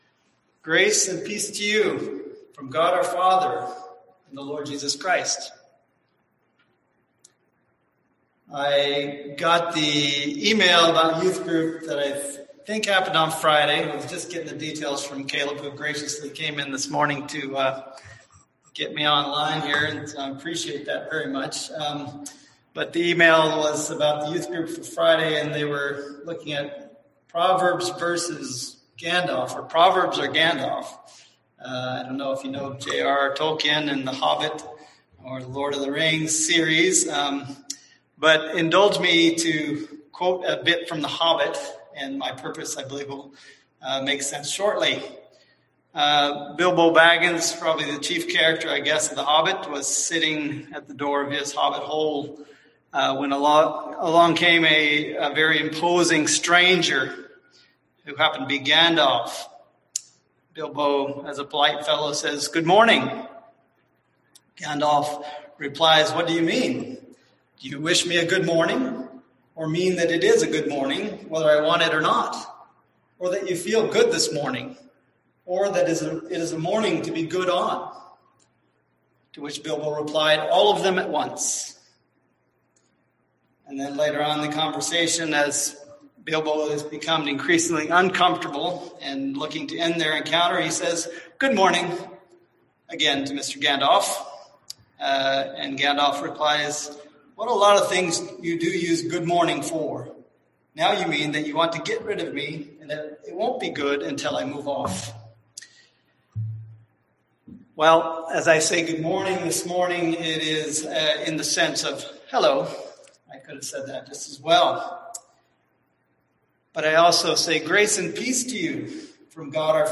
Service Type: Sunday AM Topics: Blessings , Grace , Peace